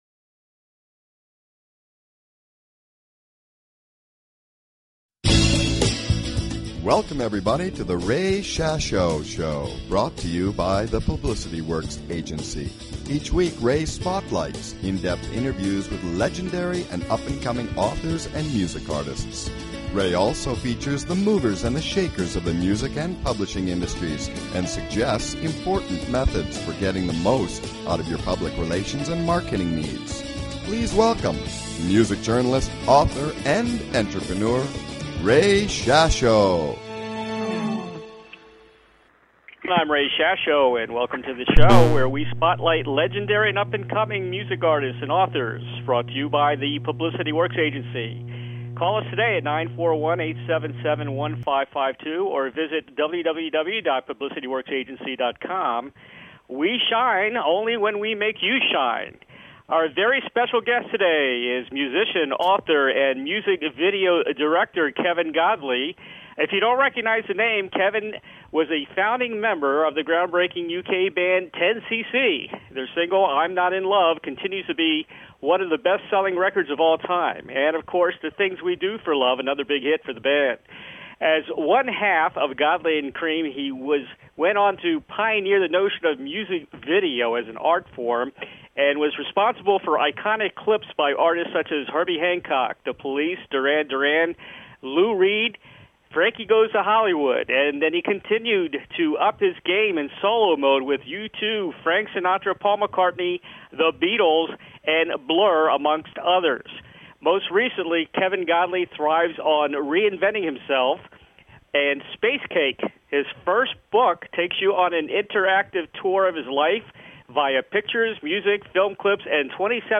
Talk Show Episode
Guest, Kevin Godley